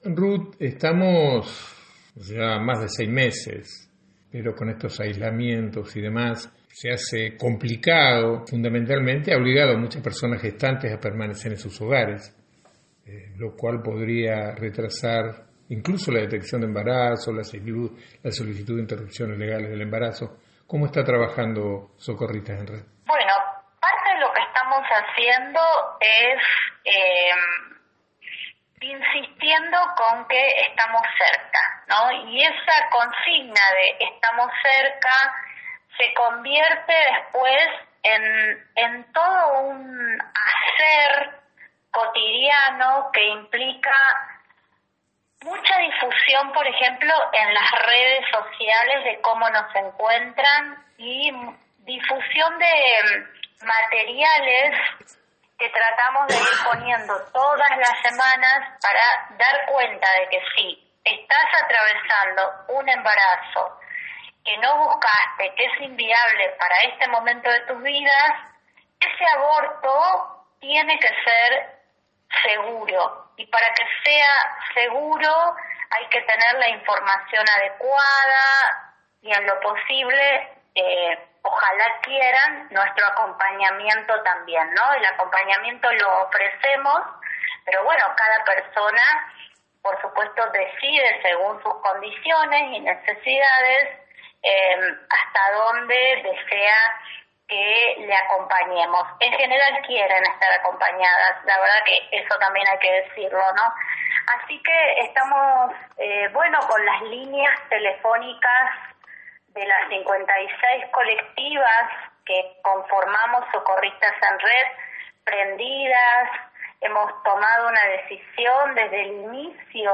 Ni la pandemia ni el aislamiento social impide la marea verde. Entrevista